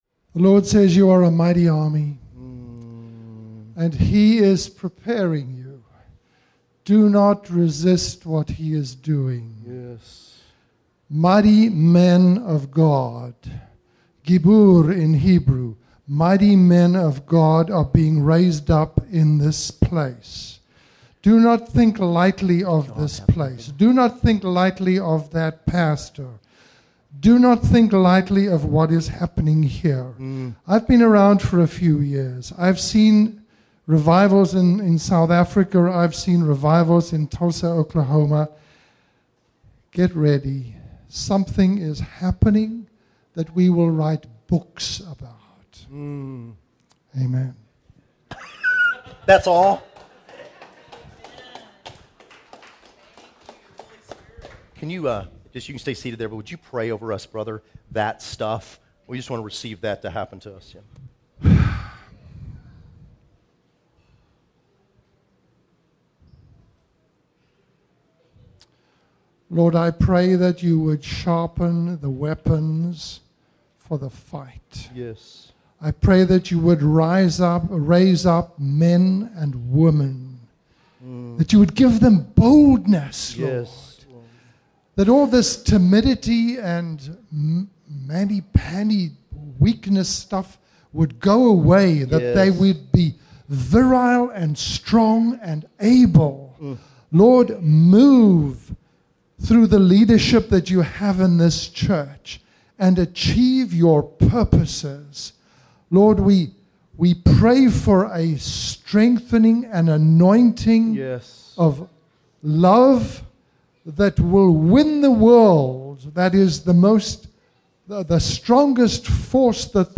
Prophetic word
July 23, 2016      Category: Encouragements      |      Location: Wichita